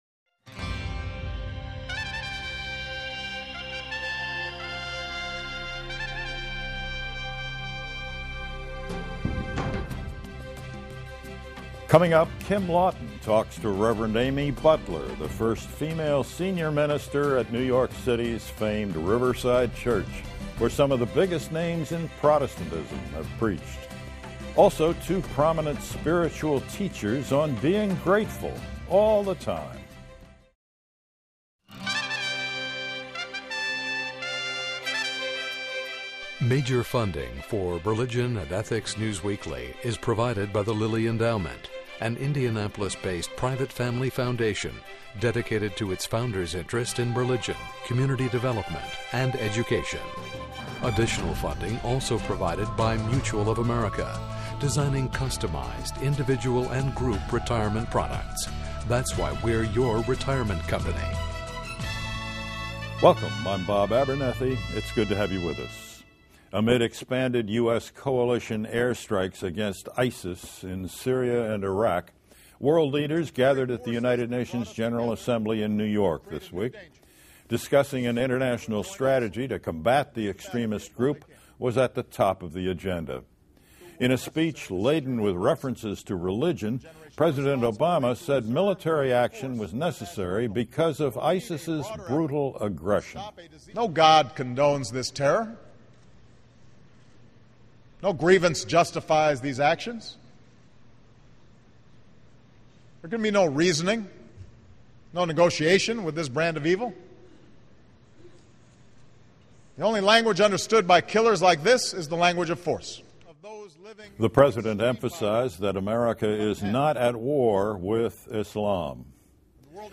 Conversation on Grateful Living